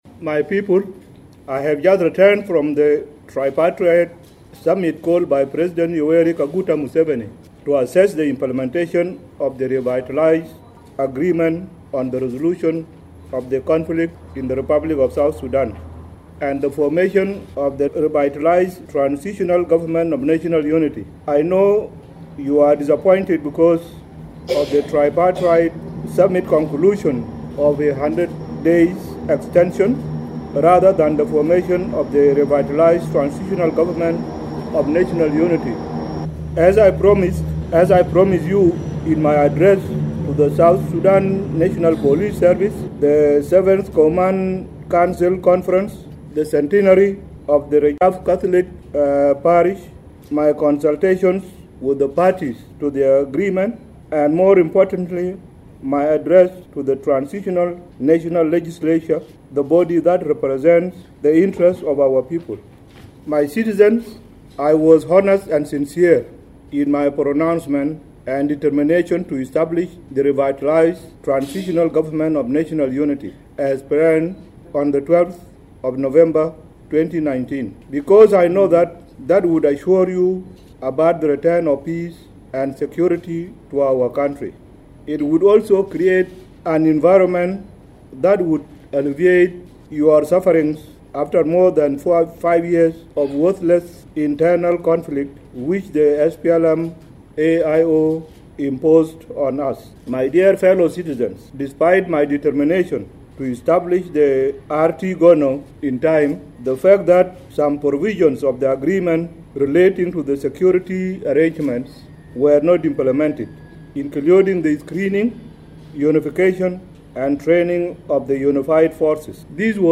Kiir’s full speech on 100-day extension
Speaking on return from Uganda at Juba International Airport, President Kiir said he was honest and sincere in his determination to establish the new government on 12th November as planned but compromised with the SPLM-IO to avert possible war. The President said a review meeting after 50 days will evaluate the implementation process.